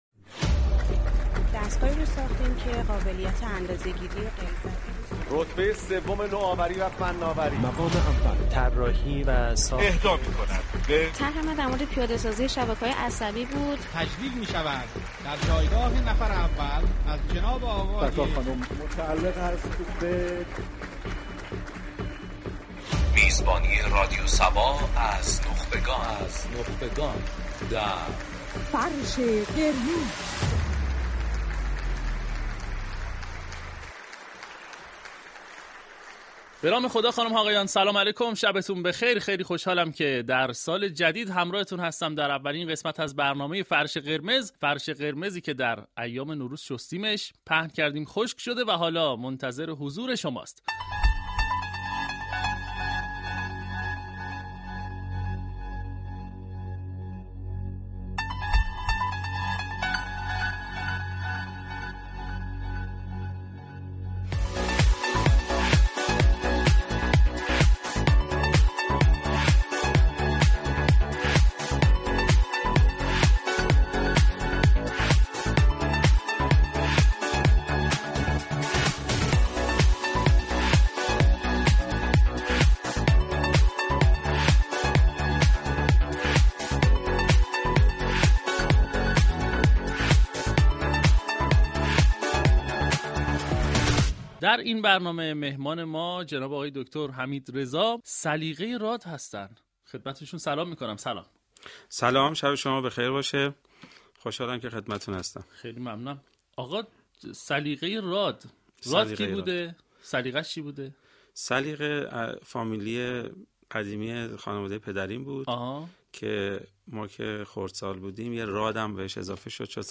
گفتگوی جذاب و صمیمی با ایشان آغاز شد كه در آن به جنبه‌های مختلف زندگی و كارهای علمی ایشان پرداخته شد.